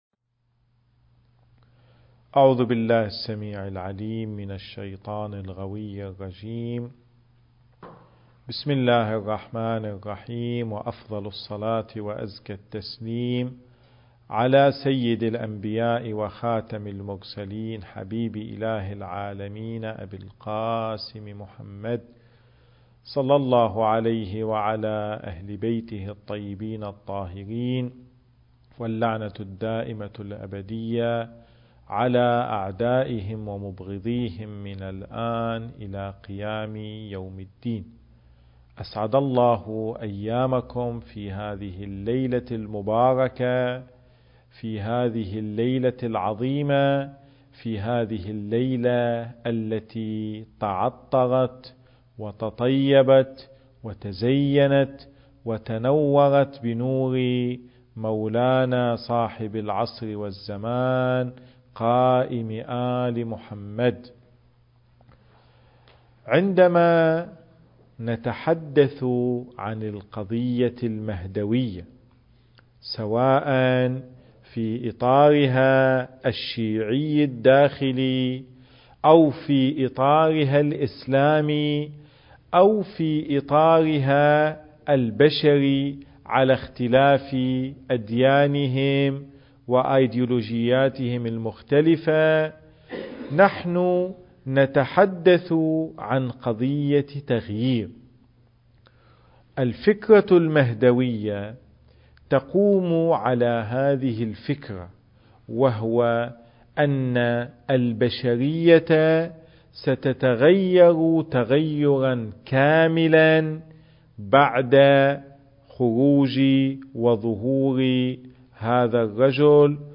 التاريخ: 1441 للهجرة المكان: جامع الرسول الأعظم (صلّى الله عليه وآله وسلم) - صفوى